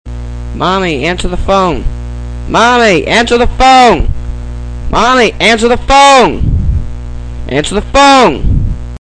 SMS Alert